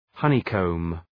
Προφορά
{‘hʌnı,kəʋm}